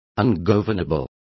Also find out how ingobernable is pronounced correctly.